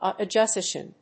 アクセント・音節àuto・suggéstion
音節au･to･sug･ges･tion発音記号・読み方ɔ̀ːtoʊsə(g)ʤésʧ(ə)n|-səʤés-